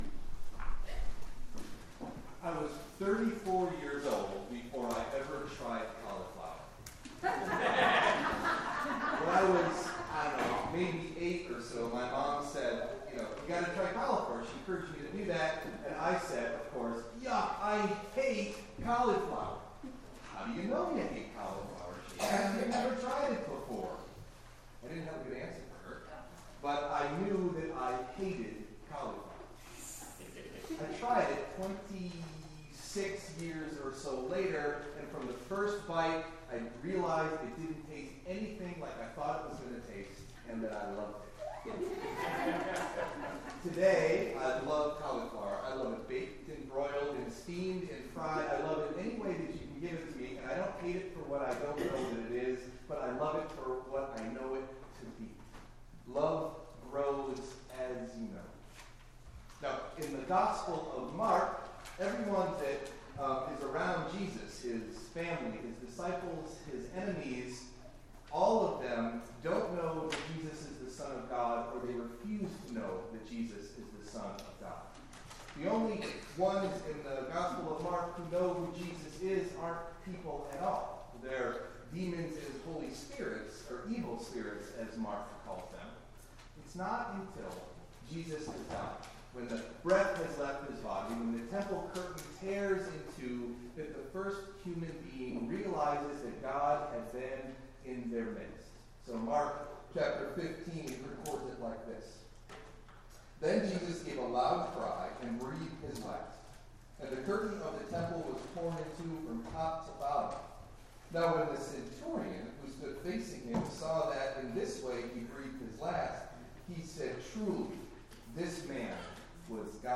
Meditation Delivered at: The United Church of Underhill (UCC and UMC)